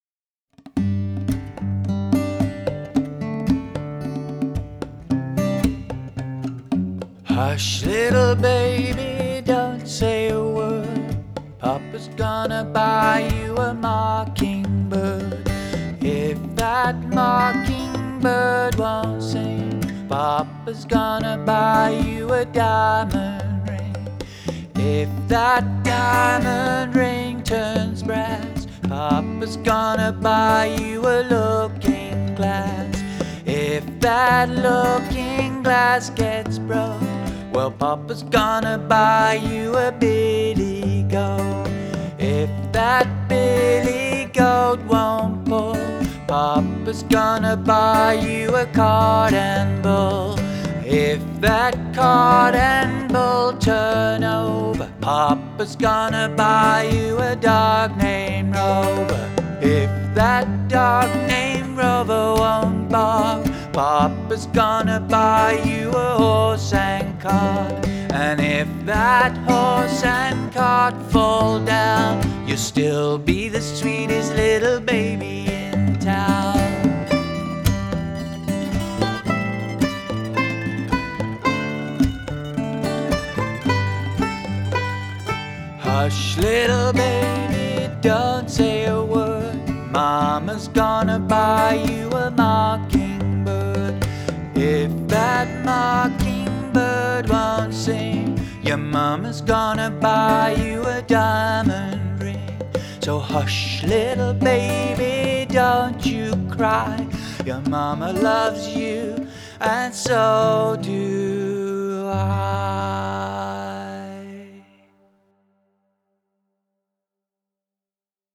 A gentle acoustic arrangement of the classic lullaby
AcousticLullaby